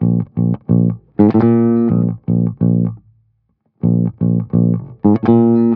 09 Bass Loop B.wav